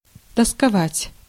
Ääntäminen
IPA : /pænt/